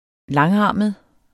Udtale [ -ˌɑˀməð ]